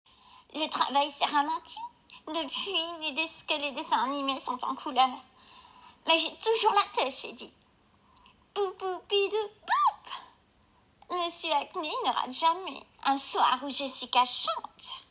Casting voix off Betty Boop